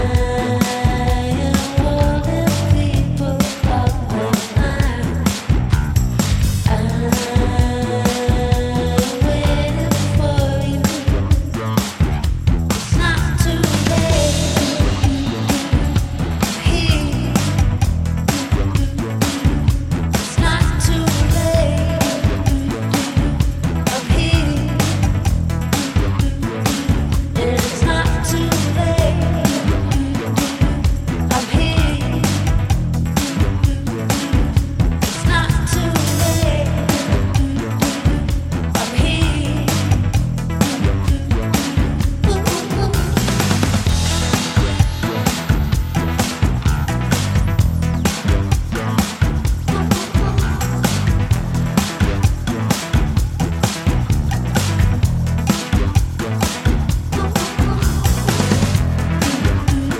acclaimed English dance collective